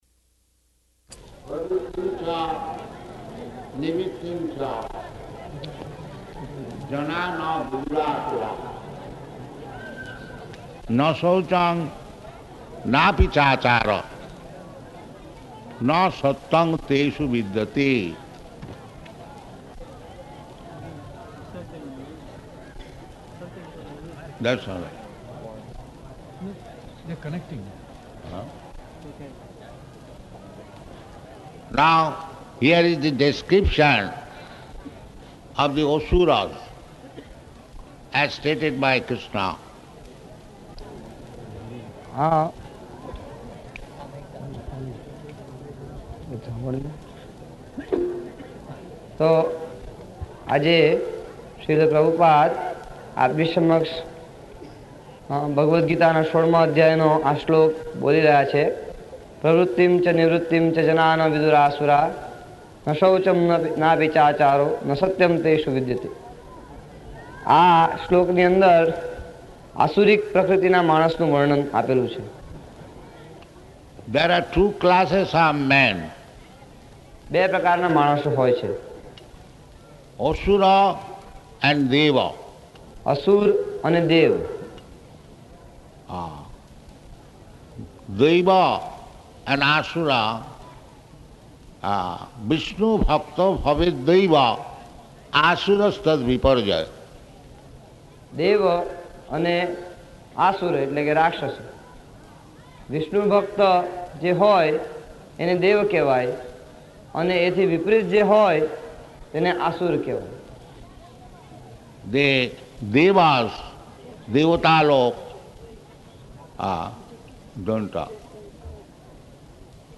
Location: Sanand
[translated throughout]